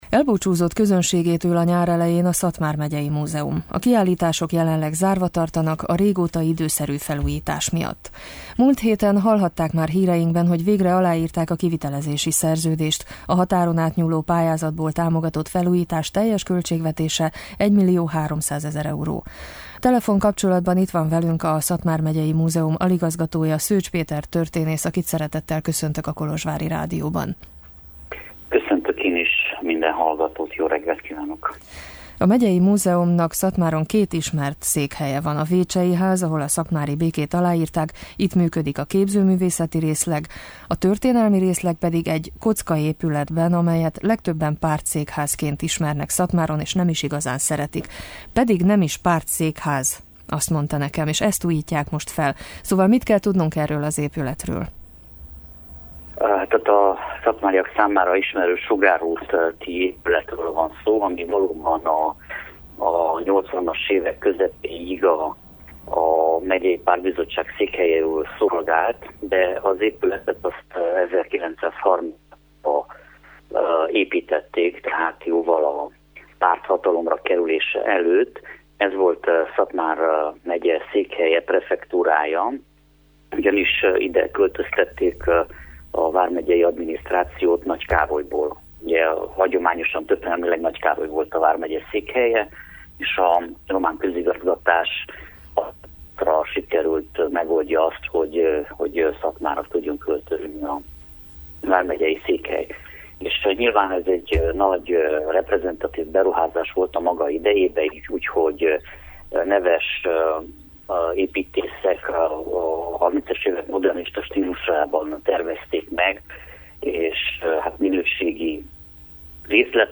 Beszélgetőtárs: